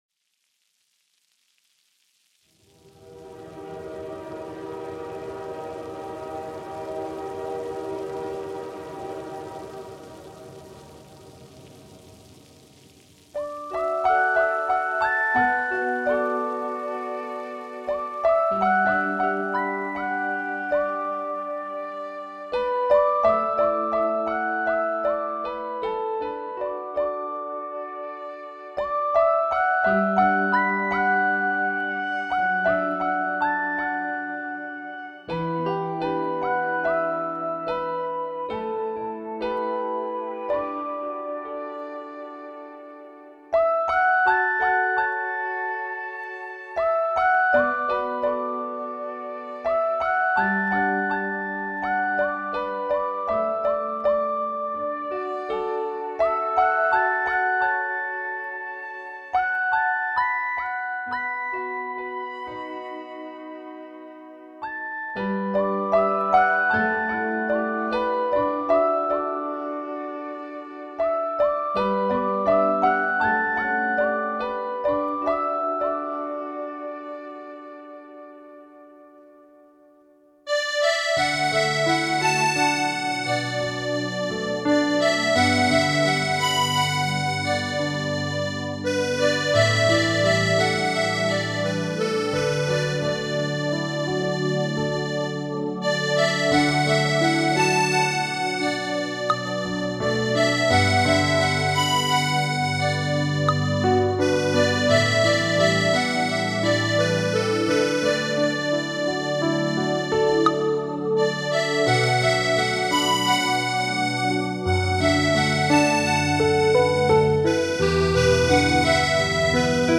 合成器、小提琴